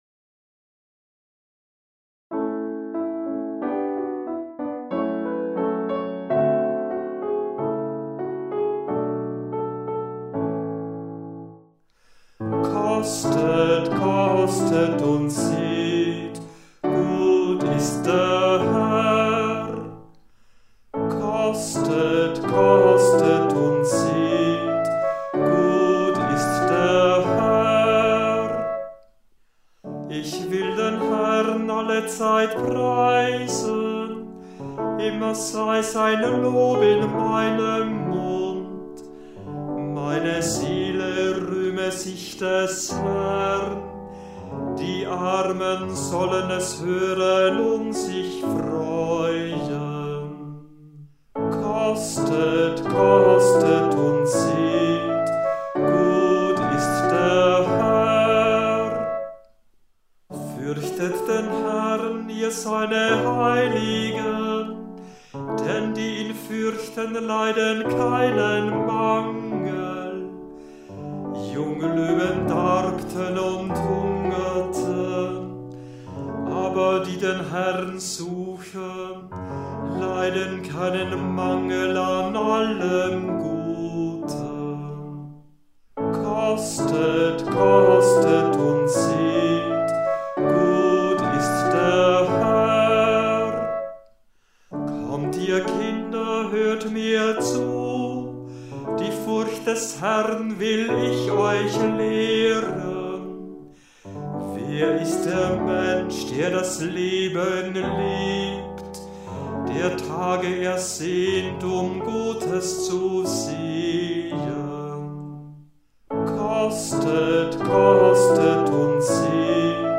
Hörbeispiele aus verschiedenen Kantorenbüchern